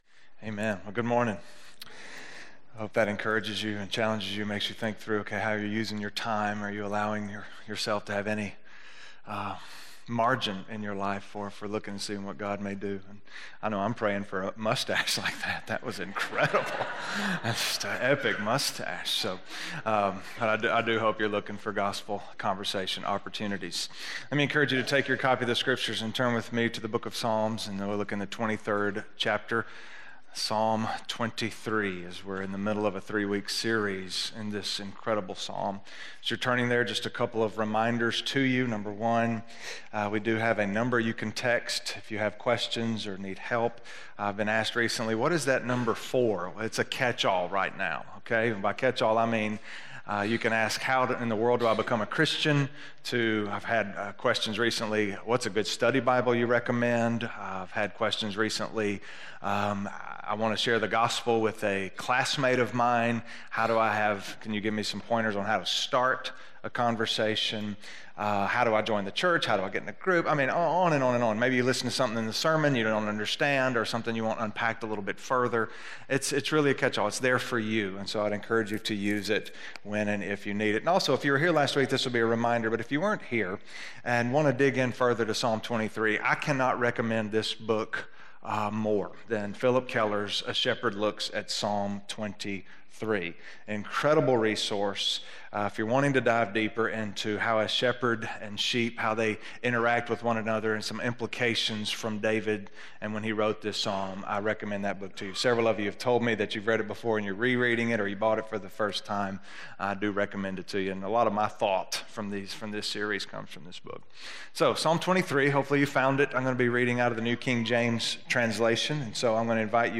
Scripture